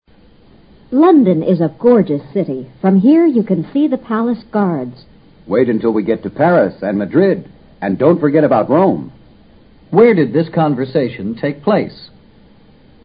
从男子说wait until … Rome 两句话可以判断他们是在London说话。